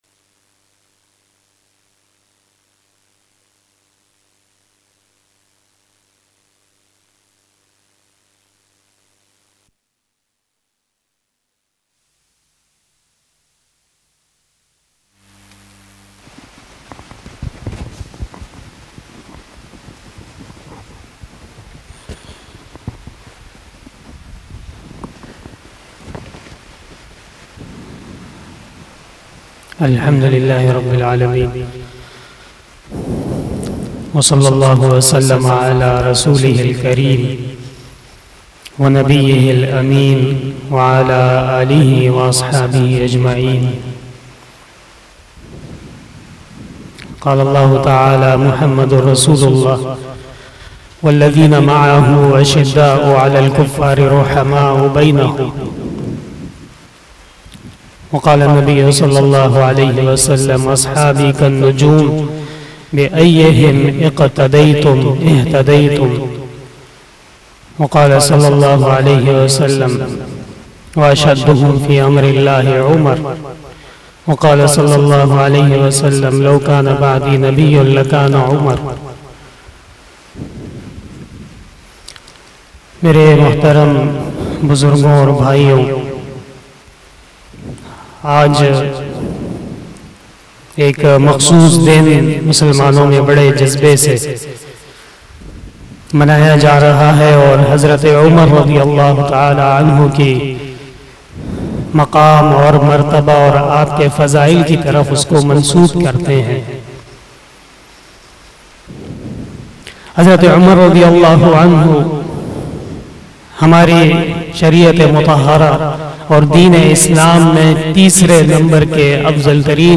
041 After Isha Namaz Bayan 10 August 2021 (02 Muharram 1443HJ) Tuesday